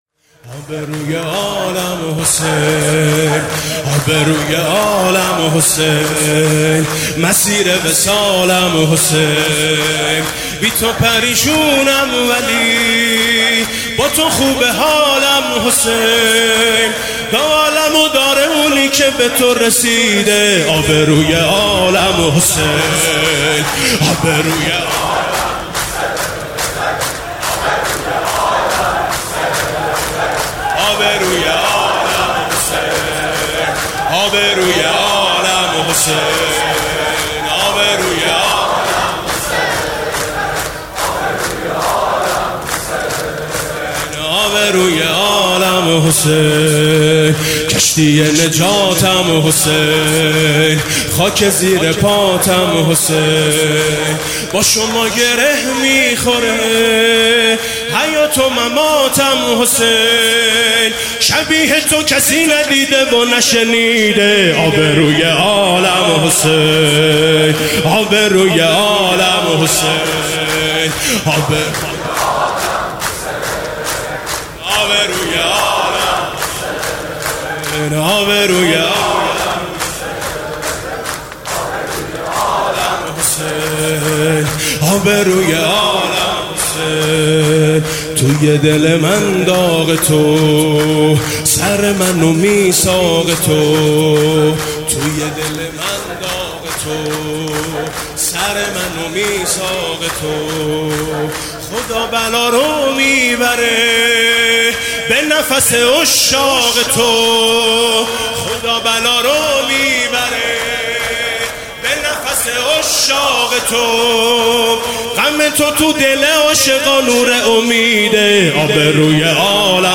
میثم مطیعی در مراسمی که به مناسبت دهه اول ماه محرم در هیأت آئین حسینی مجتمع امام رضا (ع) برگزار شد به مرثیه خوانی و روضه خوانی پرداخت که در ادامه قطعه‌ای از آن تقدیم مخاطبان می‌شود: